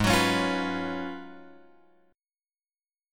G#m13 chord {4 2 4 1 x 1} chord